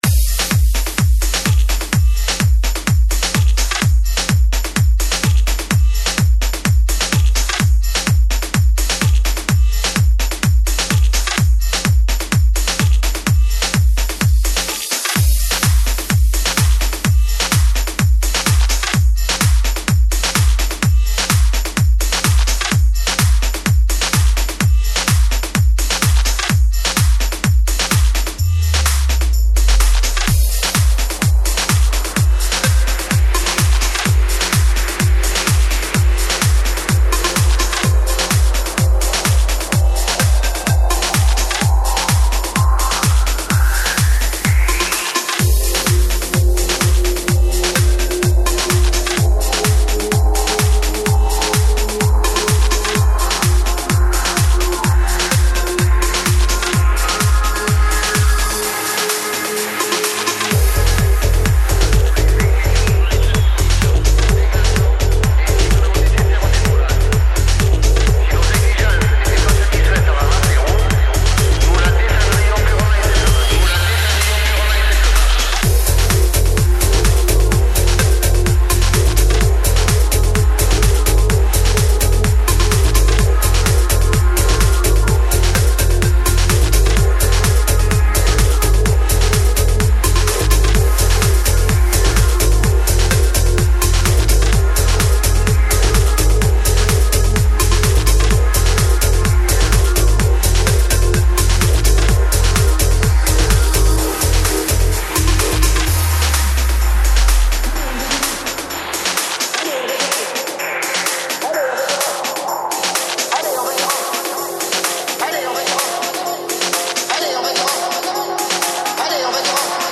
• Жанр: Электронная
house 2010 _http